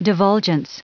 Prononciation du mot divulgence en anglais (fichier audio)
Prononciation du mot : divulgence